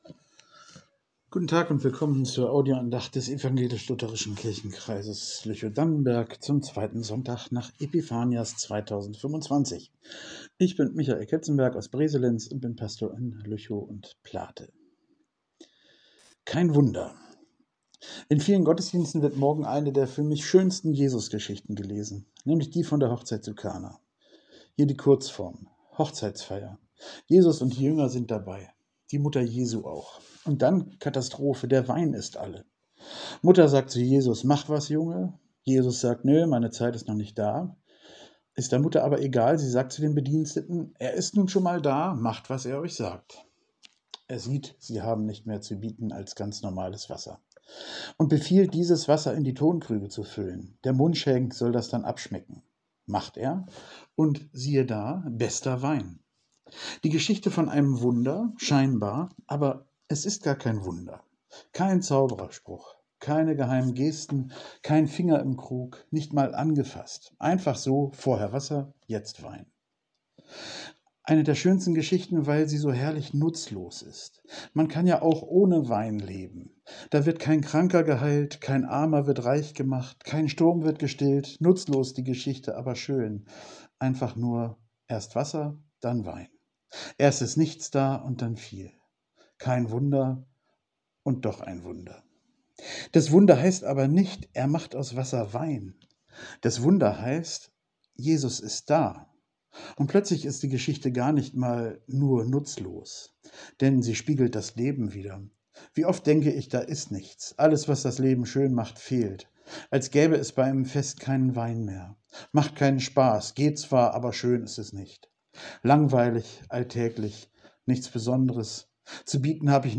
Kein Wunder ~ Telefon-Andachten des ev.-luth. Kirchenkreises Lüchow-Dannenberg Podcast